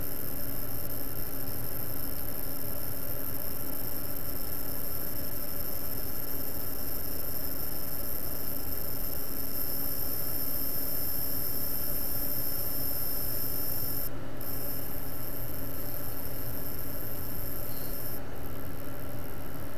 Grafikkarte summt nach Reflow
nvidia8800gtx.wav